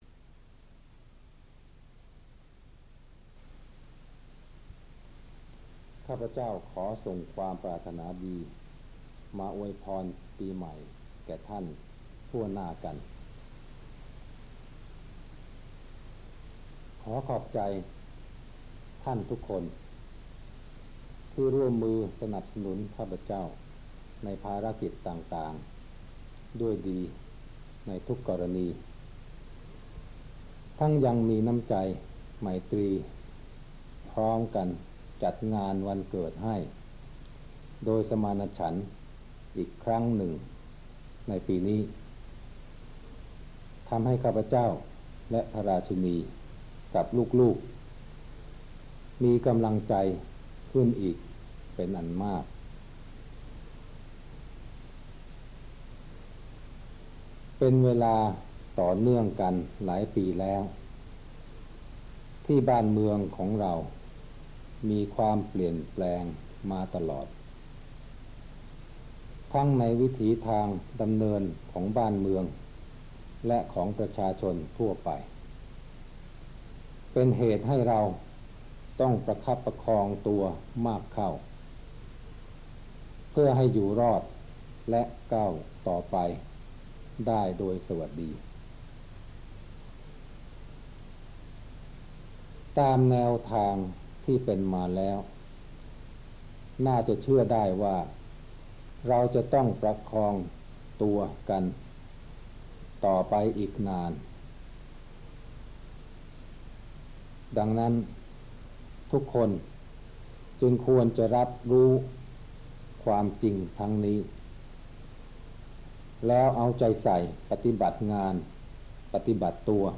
พระราชดำรัสพระบาทสมเด็จพระเจ้าอยู่หัว ในวันขึ้นปีใหม่ 2522